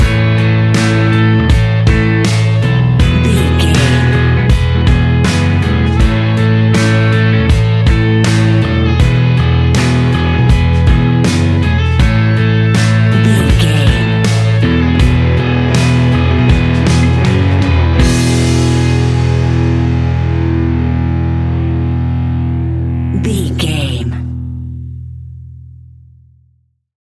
Ionian/Major
indie pop
energetic
uplifting
instrumentals
upbeat
rocking
groovy
guitars
bass
drums
piano
organ